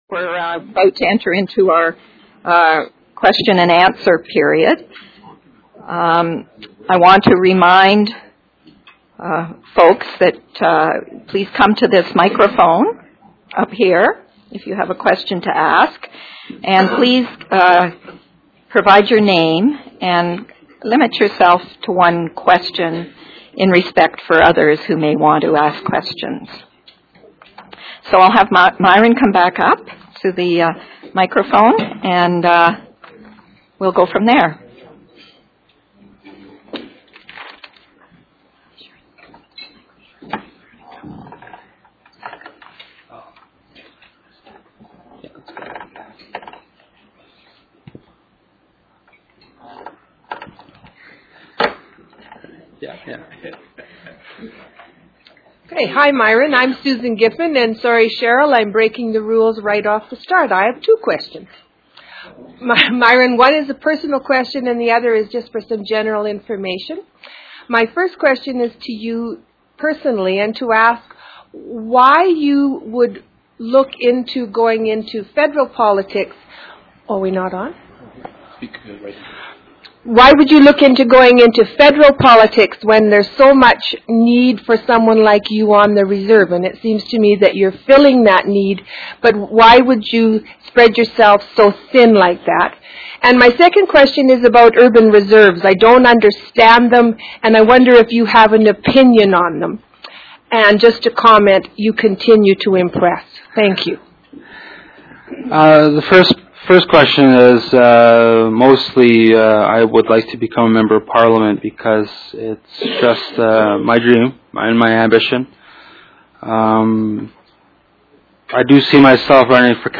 Session Audio Audio Track 1 Audio Track 2 First Nations Affairs in the 21st Century April 27